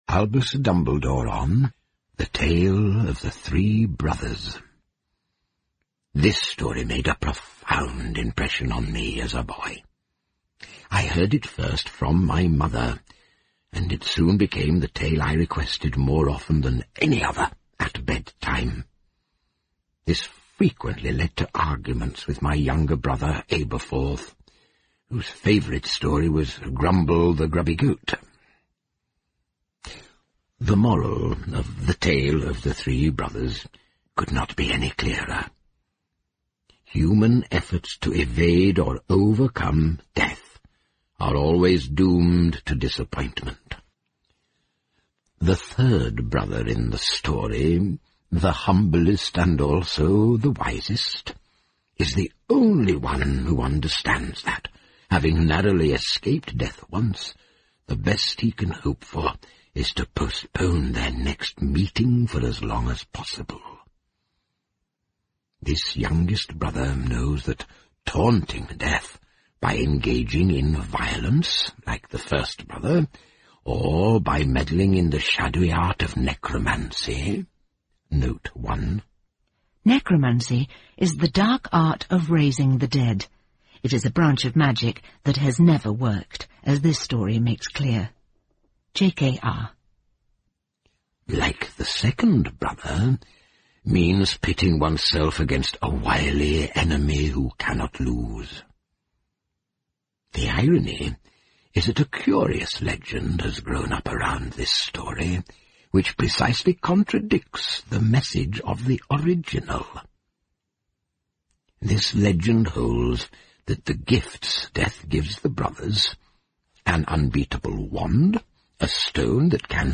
在线英语听力室诗翁彼豆故事集 第26期:三兄弟的传说(3)的听力文件下载,《诗翁彼豆故事集》栏目是著名的英语有声读物，其作者J.K罗琳，因《哈利·波特》而闻名世界。